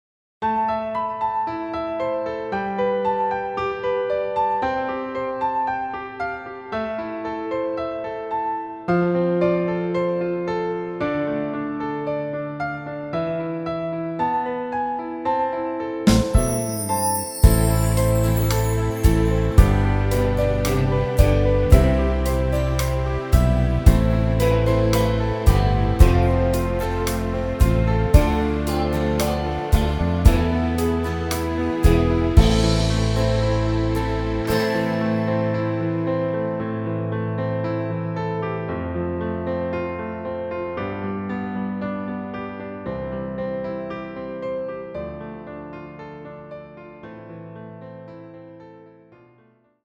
live 2015